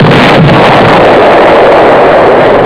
explosion.sound